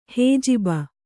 ♪ hējiba